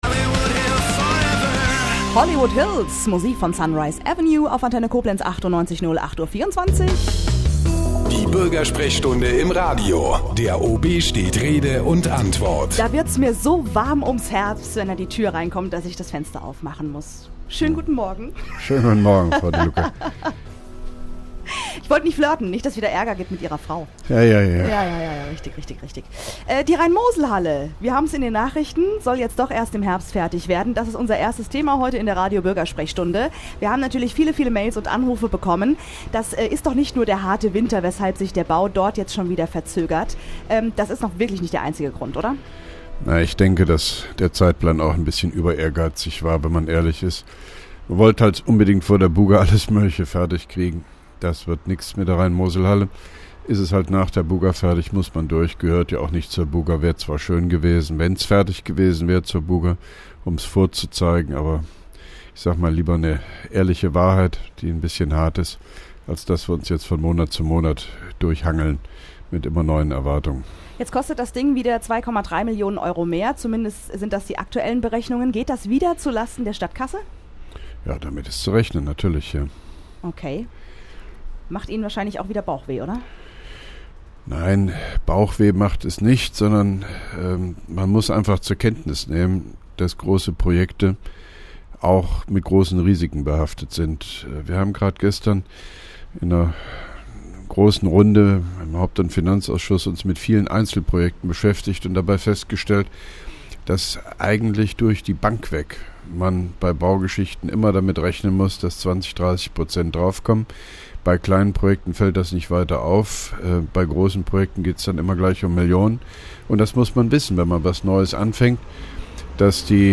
(1) Koblenzer Radio-Bürgersprechstunde mit OB Hofmann-Göttig 25.01.2011